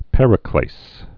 (pĕrĭ-klās, -klāz)